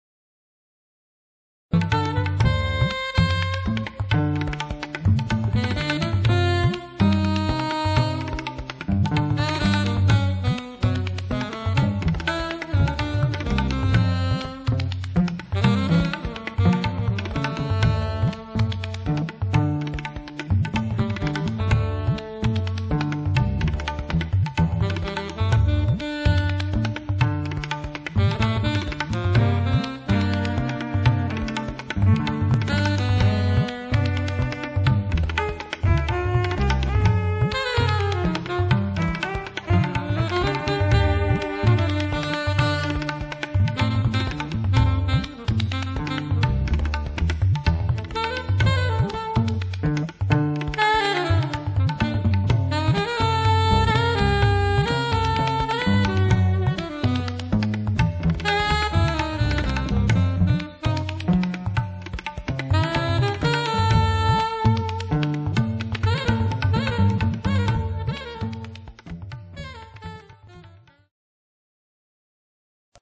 Ethno Funk
Violoncello & Sampler & Live Elektronik
Tabla & Percussion
Alt- & Sopransaxophon & Klarinette